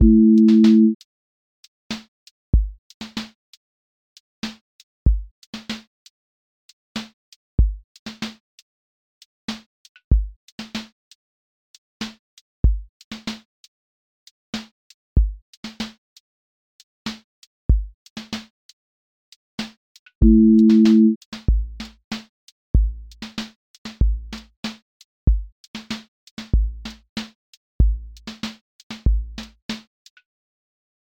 QA Listening Test r&b Template: rnb_pocket
r&b pocket with warm chord bed
• voice_kick_808
• voice_snare_boom_bap
• voice_hat_rimshot
• voice_sub_pulse
• tone_warm_body